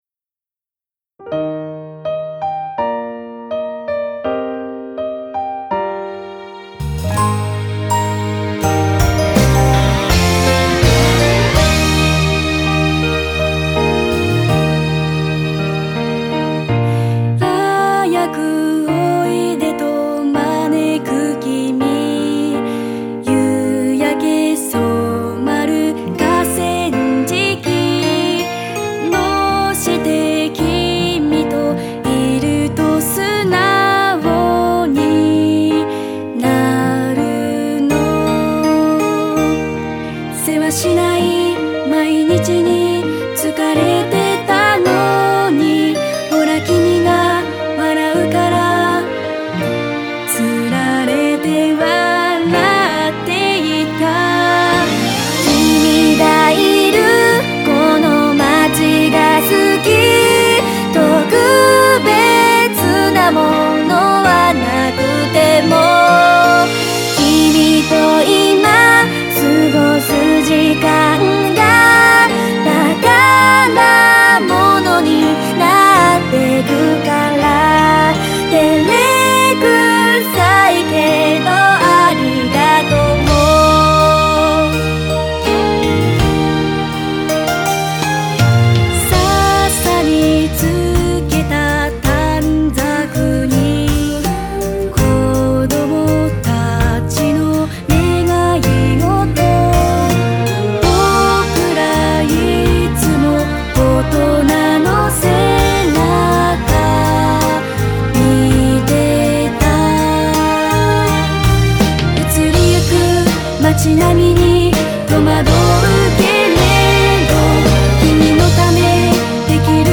メロディについては1度だけ聞いた娘が口ずさむくらい、覚えやすいです。